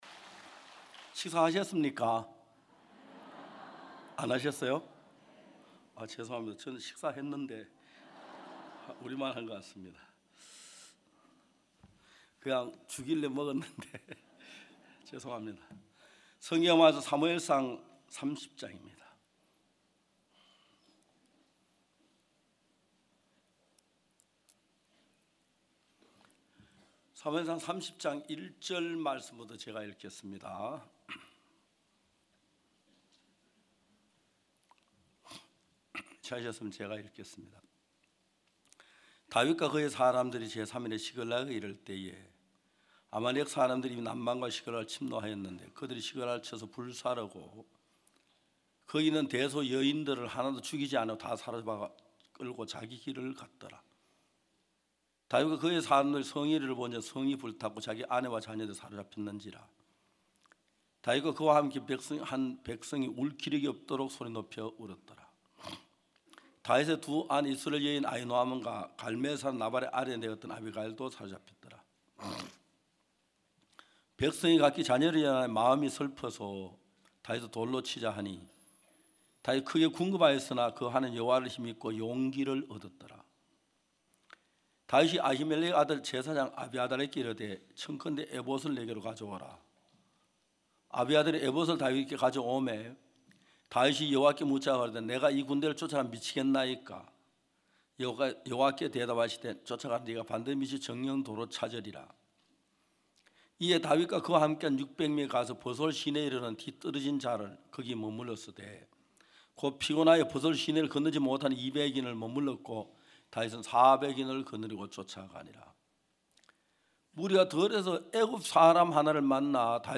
매년 굿뉴스티비를 통해 생중계 됐던 기쁜소식 선교회 캠프의 설교 말씀을 들어보세요.